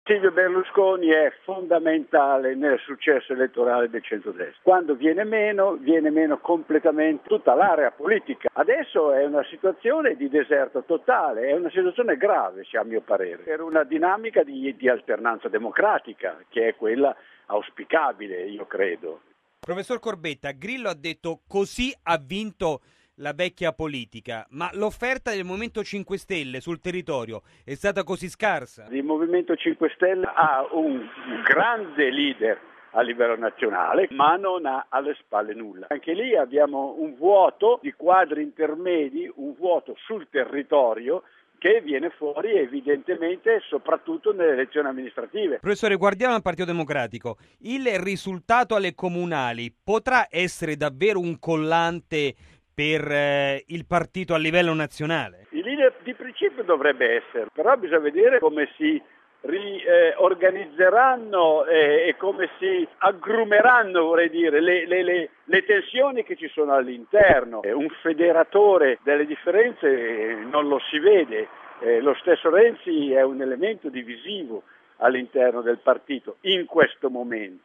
E c’è malumore nel Pdl dopo il risultato delle amministrative, mentre nel Pd ci si interroga su come massimizzare il risultato. Abbiamo sentito il politologo